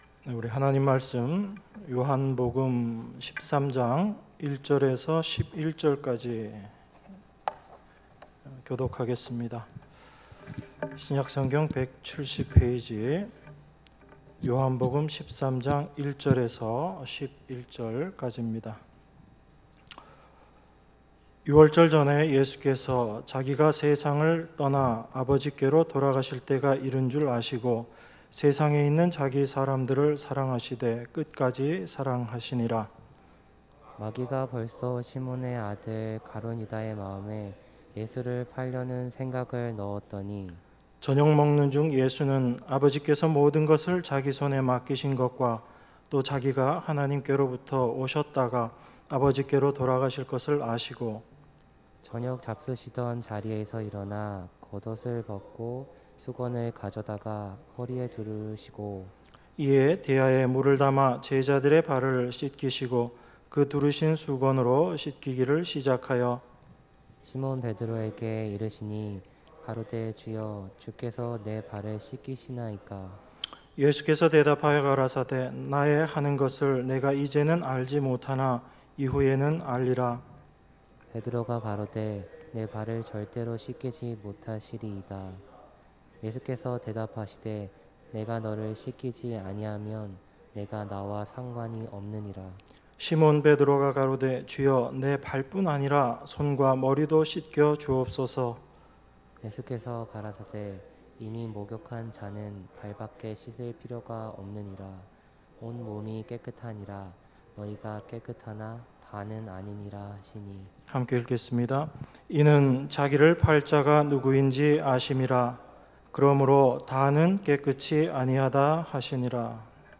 주일예배 요한복음 13:1~11